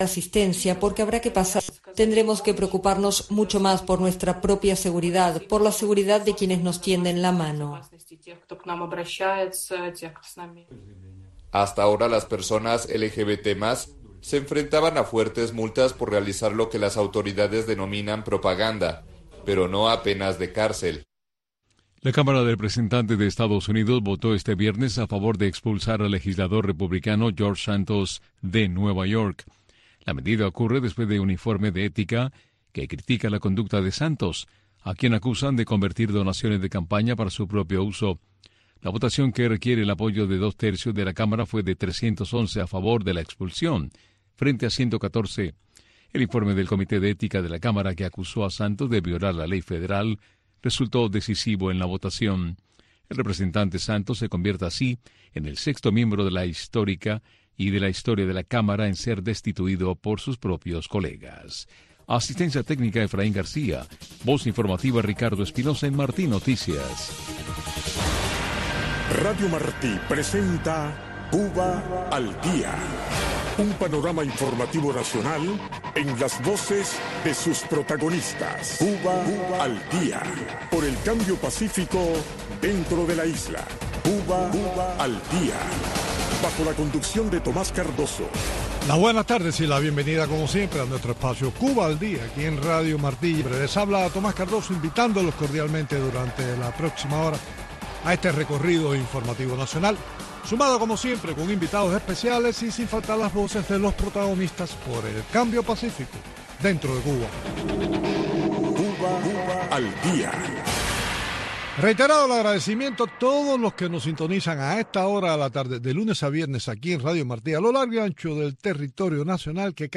en este espacio informativo en vivo